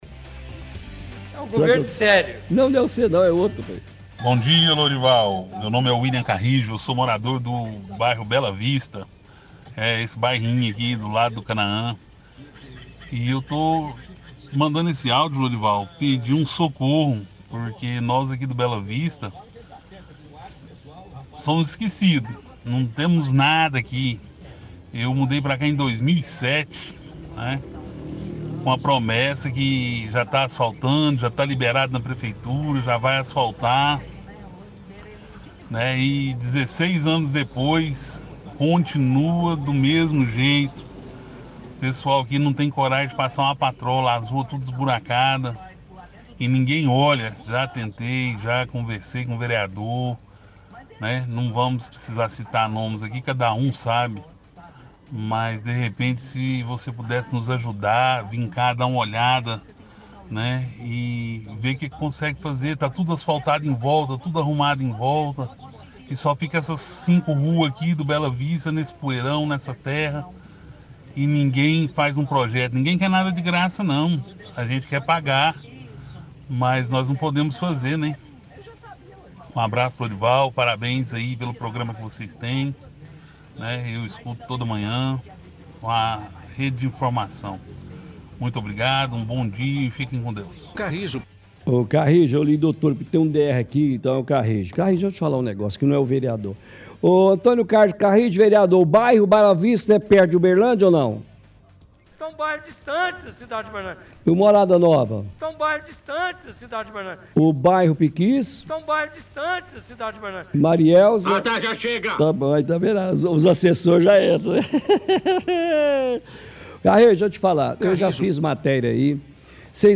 – Ouvinte do bairro Bela Vista pede socorro porque bairro é esquecido pelo poder público.